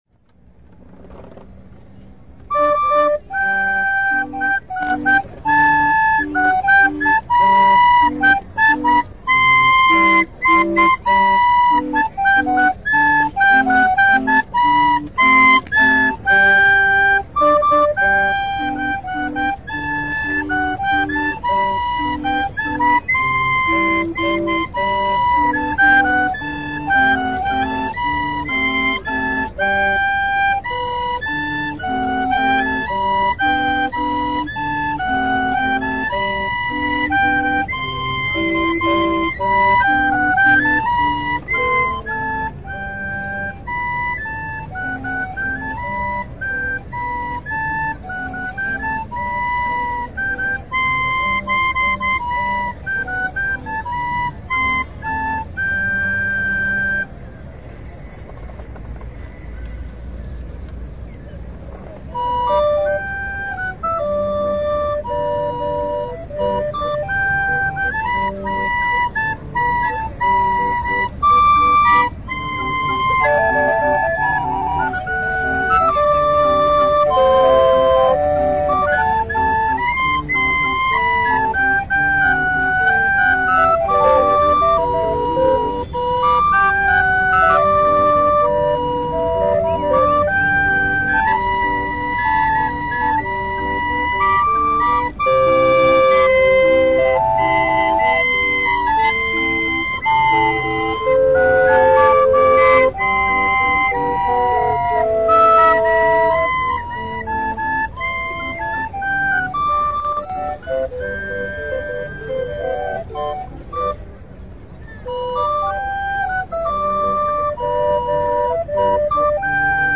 Organillero
Canciones como Las mañanitas, Perfume de Gardenias, Ella y otras de autores populares suenan a traves del organillo que ha amenizado a peatones de la Ciudad de Mexico durante decadas.
El organillero sostiene el pesado instrumento musical en un monopie y le da vuelta al manubrio, mientras otro vestido con el mismo uniforme en color beige y gorra en mano pide dinero a los transeúntes.
Los invitamos a transportarse a la nostalgia a traves de este instrumento musical, que al girar un manubrio hace mover sobre su eje un cilindro con puas que mueven a su vez a unos macillos que repercuten en las cuerdas de piano, que se sitúan en el interior de un cajón haciendolas sonar.
Lugar: Zócalo de la Ciudad de Mexico
Equipo: Minidisc NetMD MD-N707, micrófono de construcción casera (más info)